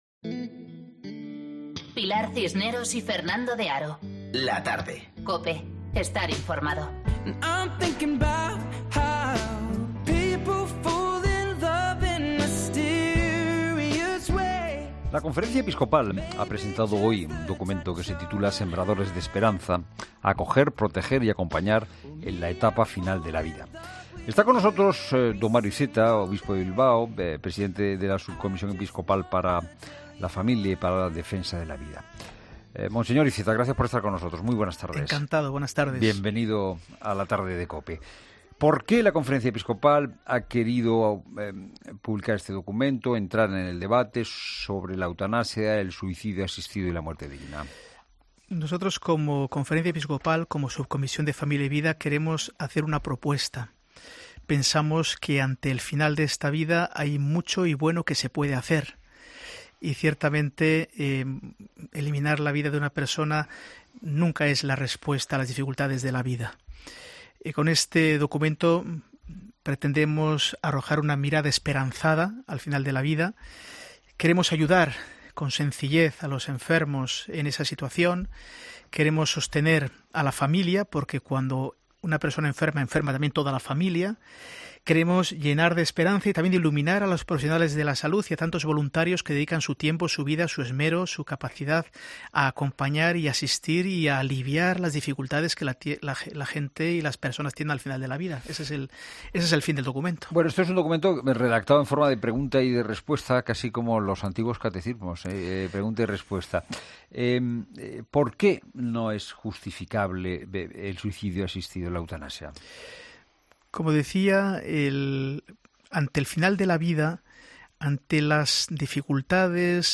Mario Iceta ha pasado por 'La Tarde' para explicar el documento presentado este miércoles por la CEE